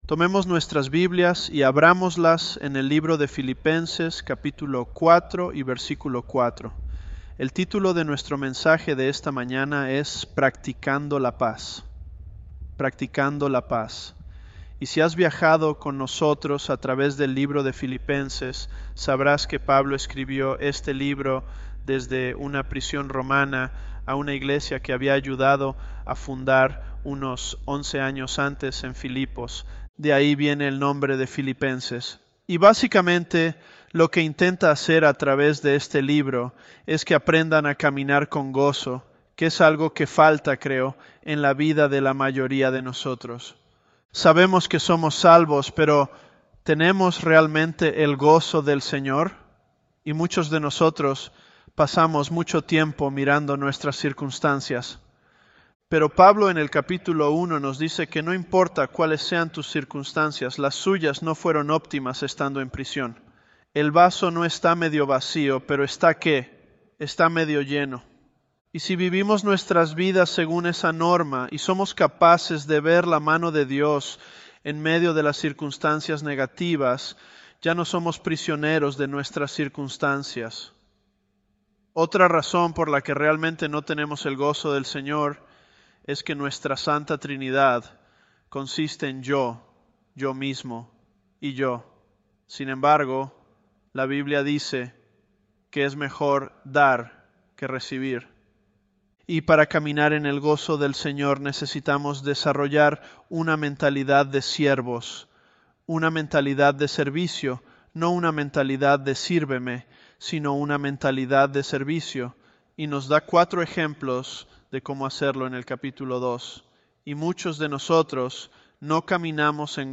Sermons
Elevenlabs_Philippians011.mp3